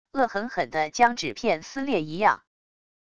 恶狠狠的将纸片撕裂一样wav音频